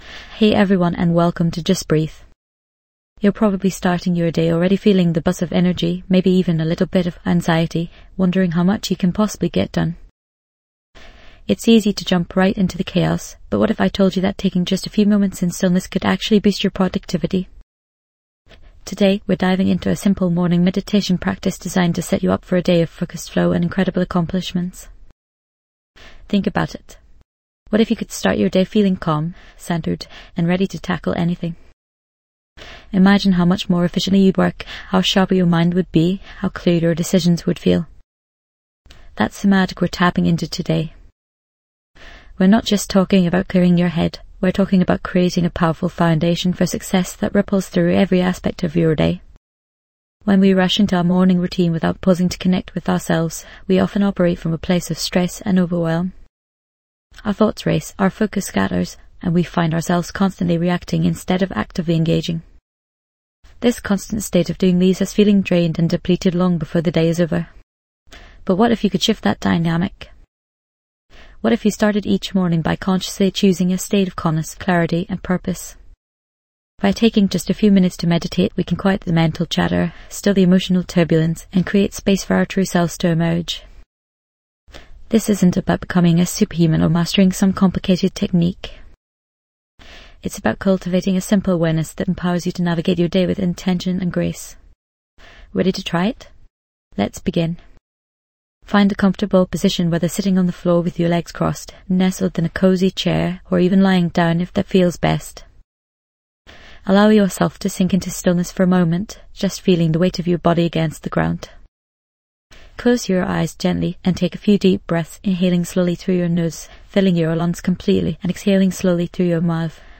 Immerse yourself in the calming sounds of guided meditation, designed to help you boost focus, clarity, and productivity throughout the day.